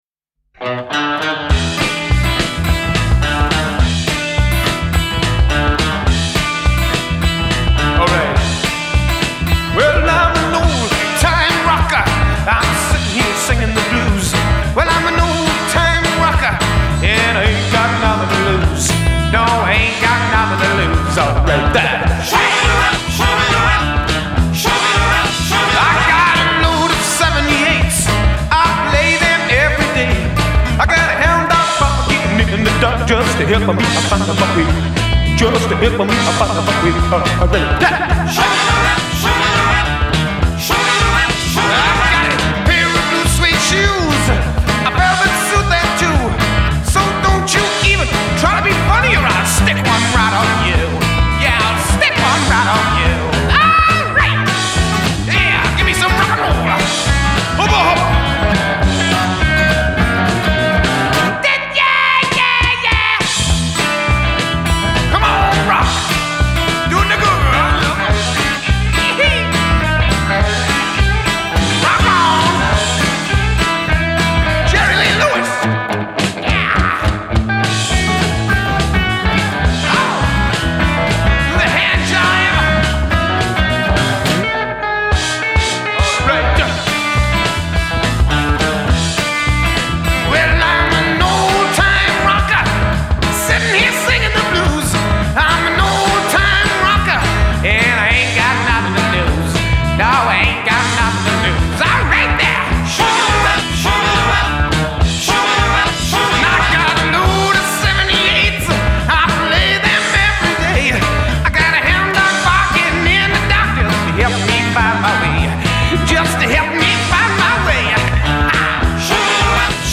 Genre: Rock, Glam Rock